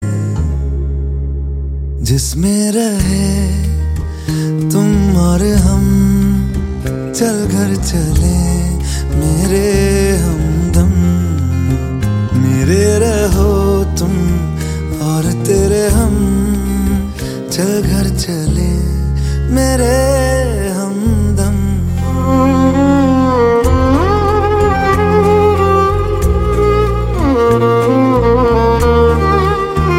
hindi bollywood silent guy Category